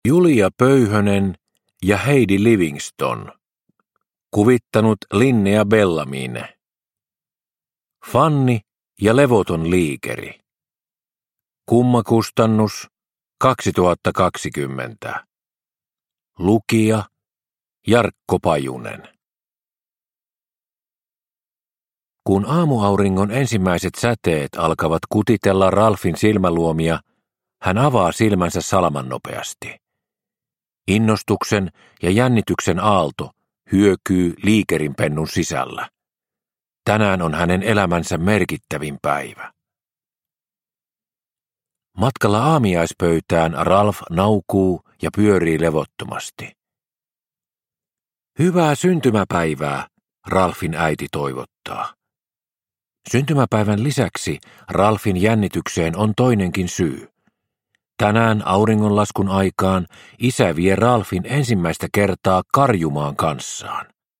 Fanni ja levoton liikeri – Ljudbok – Laddas ner